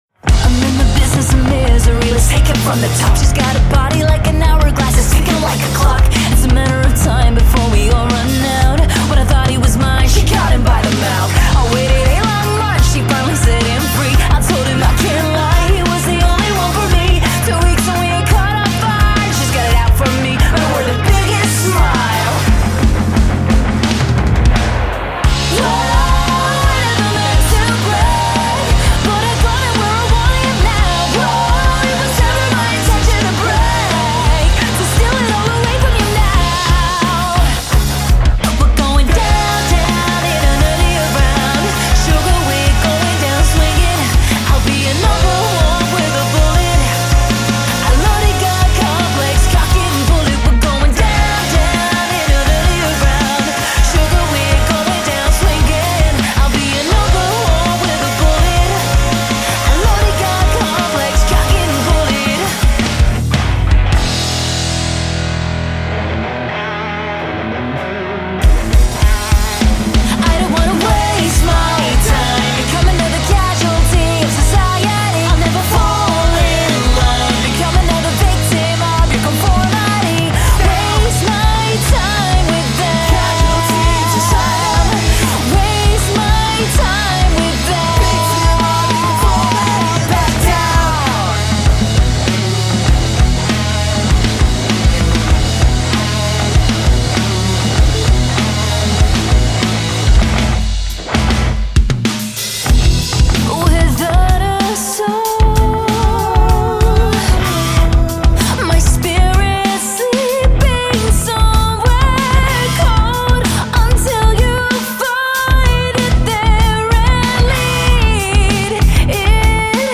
Pop Punk Heaven with a dash of emo & rock bangers!
• Unique female-fronted alt-rock emo pop punk cover band
• Impressive live harmonies
• Includes rock screaming!
Female Vocals, Backing Vocals/Guitar, Bass, Drums